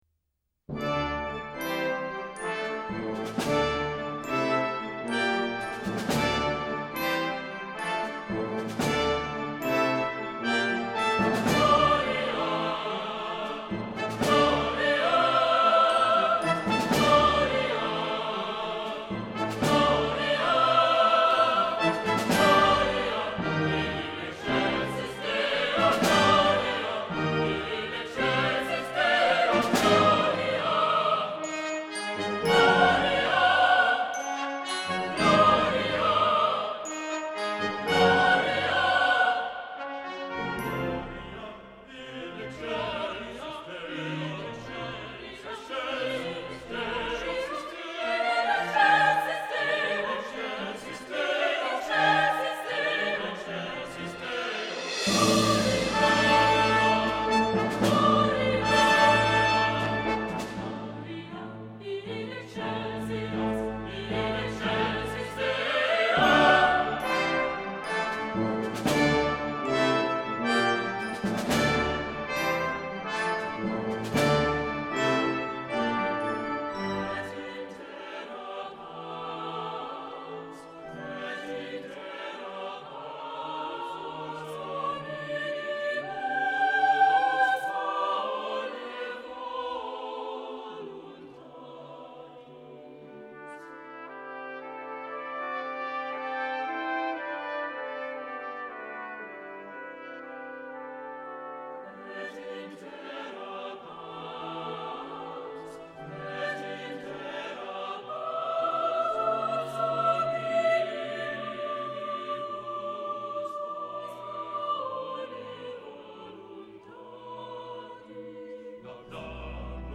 Un magnifique programme de musique sacrée des XXe et XXIe siècles ouvre la série Les Grands concerts 2016-2017 ...
Musique pour chœur, cuivres, orgue et percussion.
chœur, ensemble de cuivres, orgue et percussion
Ces œuvres seront chantées en alternance avec un programme entièrement a cappella par le Chœur de chambre du Québec.